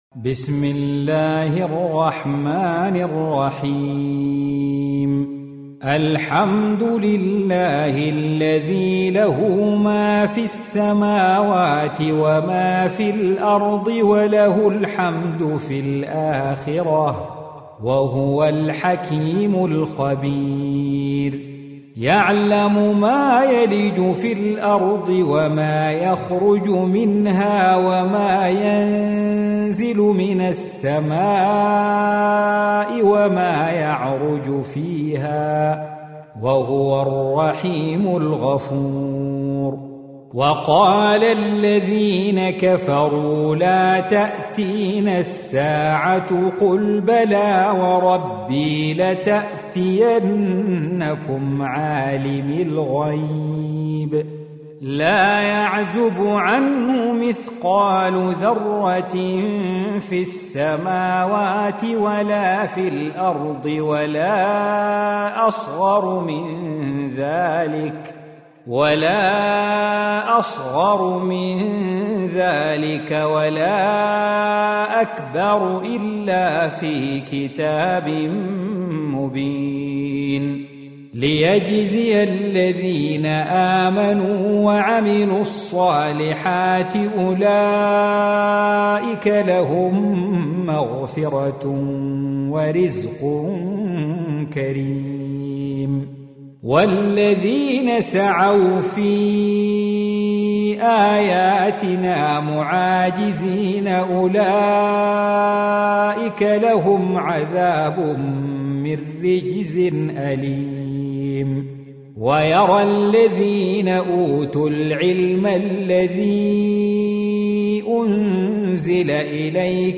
Surah Repeating تكرار السورة Download Surah حمّل السورة Reciting Murattalah Audio for 34. Surah Saba' سورة سبأ N.B *Surah Includes Al-Basmalah Reciters Sequents تتابع التلاوات Reciters Repeats تكرار التلاوات